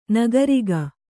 ♪ nagariga